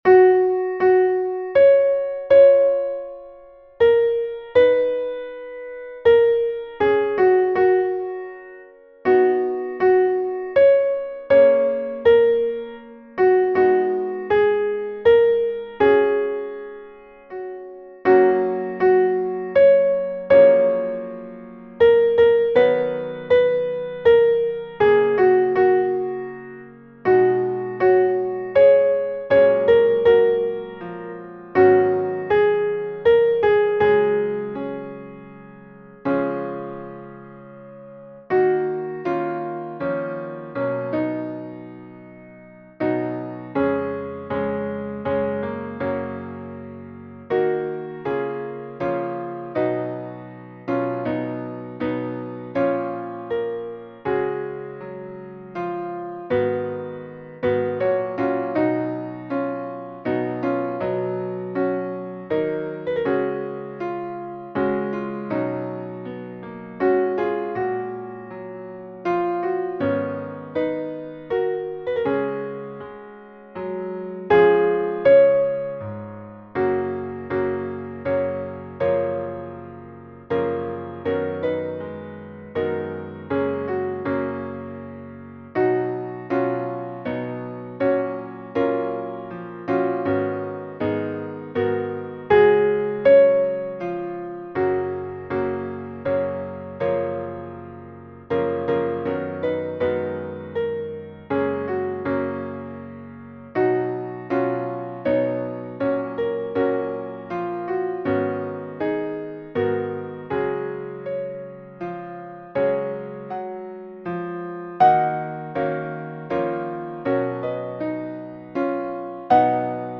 Versions piano